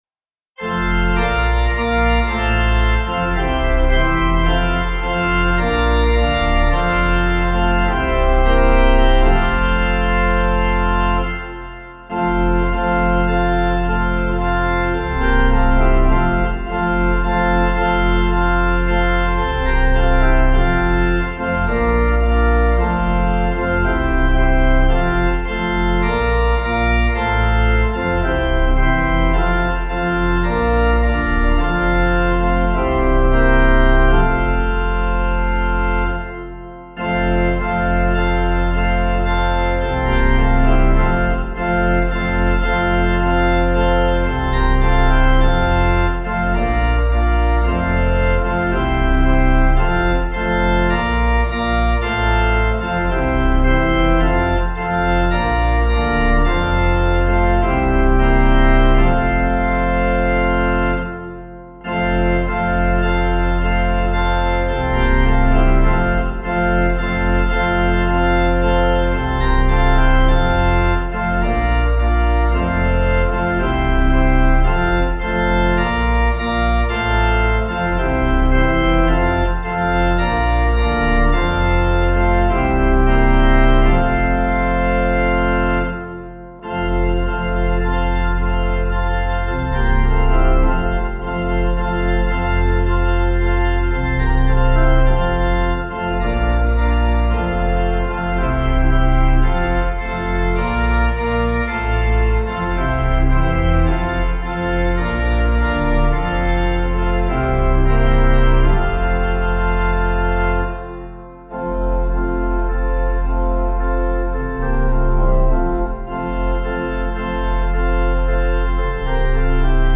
Practice singing the hymns for this Sunday’s worship services using the sheet music and audio accompaniment below.